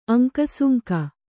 ♪ aŋkasuŋka